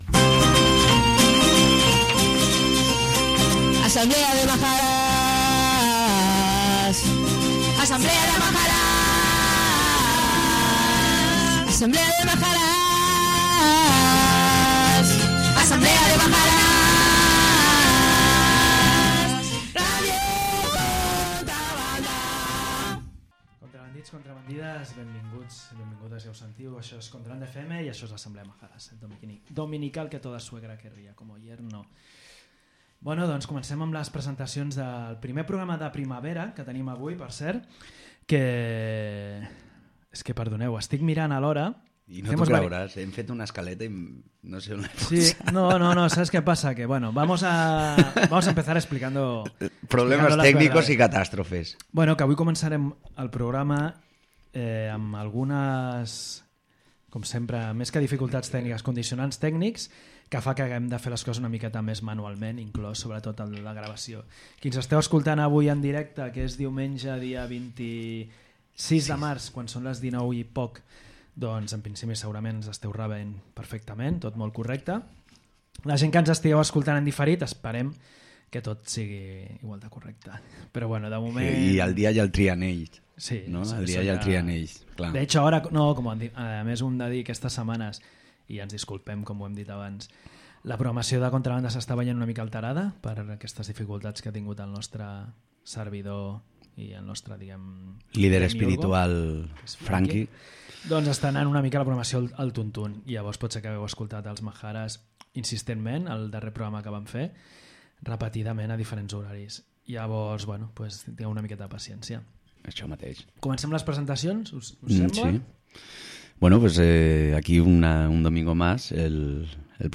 També comptem amb Alien Population, en format trio que ens porten el seu funk fusió marcià.